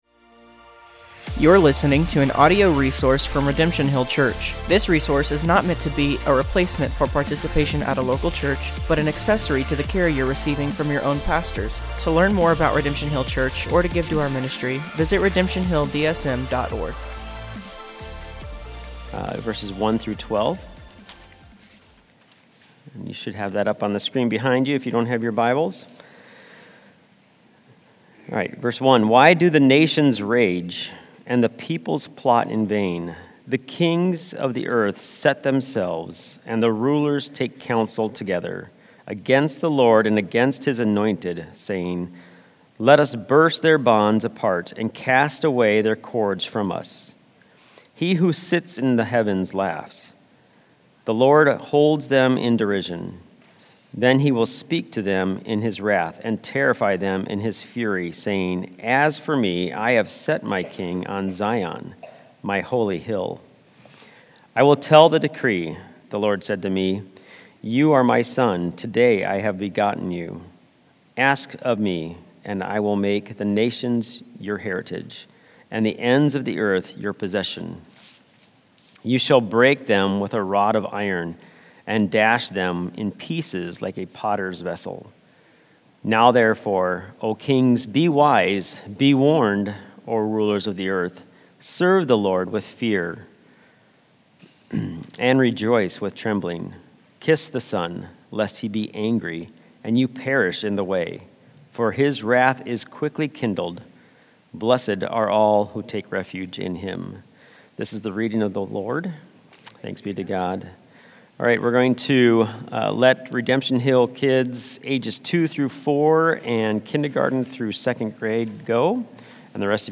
Sermons | Redemption Hill Church Des Moines, IA